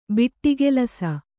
♪ biṭṭigelas